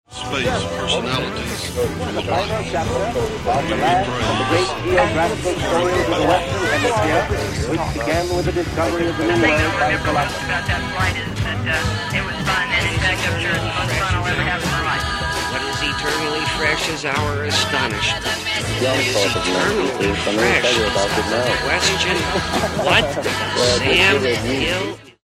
rain & thunder over Cream Hill Pond